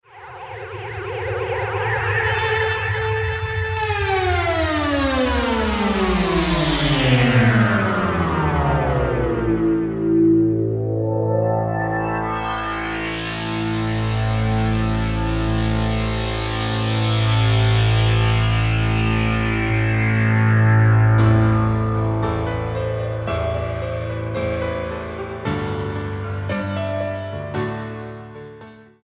STYLE: Hard Music
prog rock